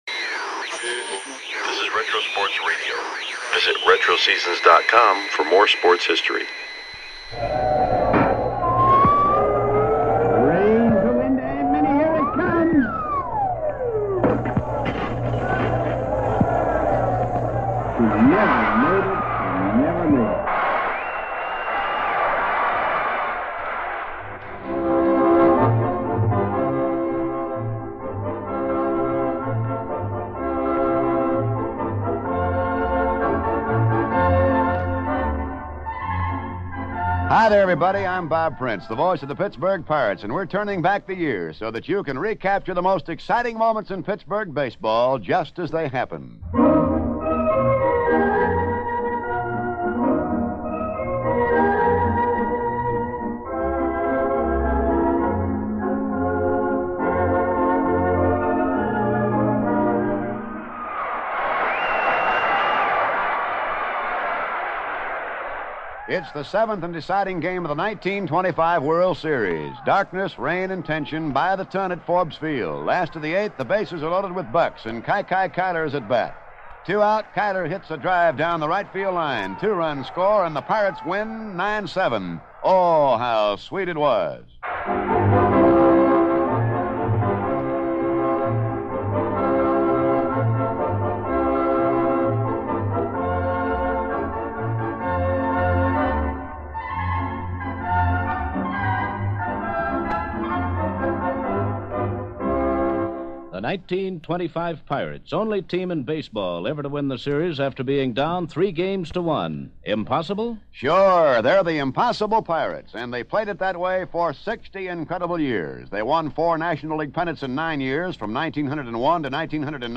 1971-Apr-12 - CHC/LAD - Chicago Cubs vs Los Angeles Dodgers - Classic Baseball Radio Broadcast – Retro Sports Radio: Classic Games from History – Lyssna här
- Monday, April 12, 1971 - Attendance: 15,407 - Venue: Dodger Stadium - - Radio Announcers▬▬▬▬▬▬▬▬▬▬▬▬▬▬▬ - Vin Scully - Jerry Doggett - - Managers▬▬▬▬▬▬▬▬▬▬▬▬▬▬▬ - Leo Durocher - Walt Alston - - Starting Lineups▬▬▬▬▬▬▬▬▬▬▬▬▬▬▬ - 1971 Chicago Cubs - 1 - SS - Don Kessinger - 2 - 2B - Glenn Beckert - 3 - LF - Billy Williams - 4 - 3B - Ron Santo - 5 - 1B - Joe Pepitone - 6 - RF - Johnny Callison - 7 - CF - José Ortiz - 8 - C - J.C. Martin - 9 - P - Ken Holtzman - - 1971 Los Angeles Dodgers - 1 - SS - Maury Wills - 2 - RF - Manny Mota - 3 - 1B - Wes Parker - 4 - LF - Dick Allen - 5 - C - Bill Sudakis - 6 - 2B - Jim Lefebvre - 7 - 3B - Steve Garvey - 8 - CF - Bill Russell - 9 - P - Sandy Vance